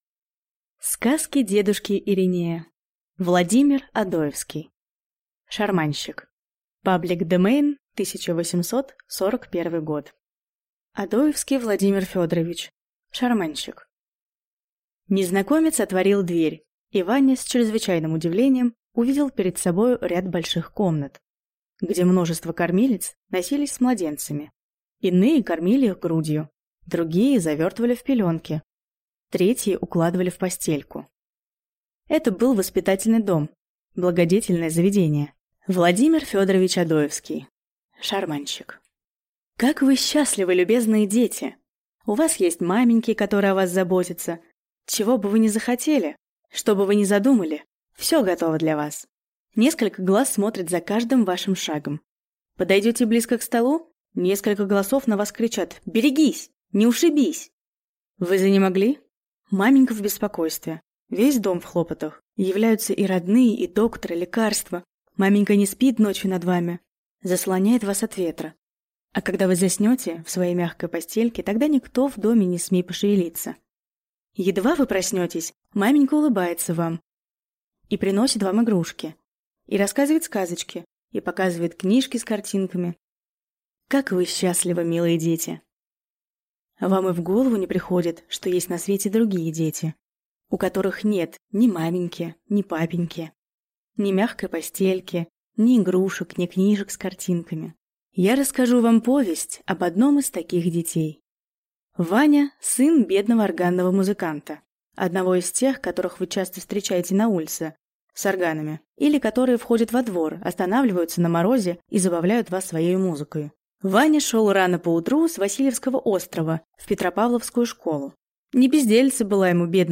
Аудиокнига Шарманщик | Библиотека аудиокниг